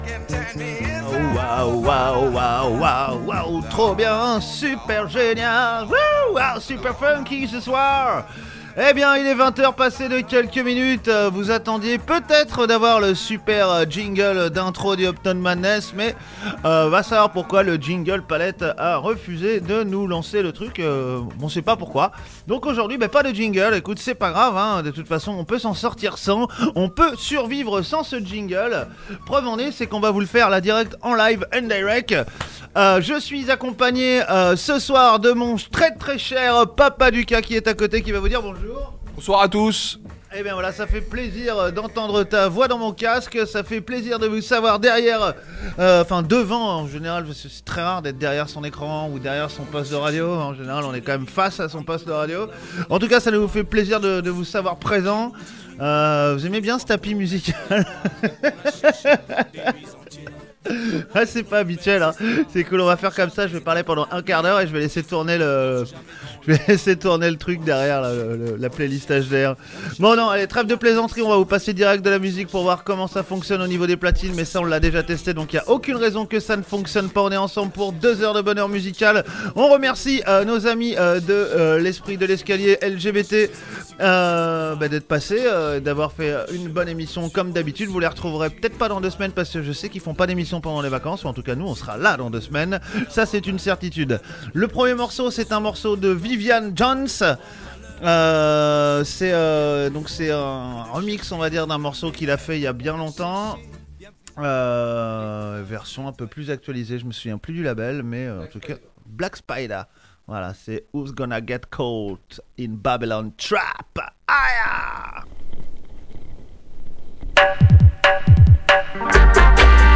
grosses sélections Roots et Dub inna Sound System Style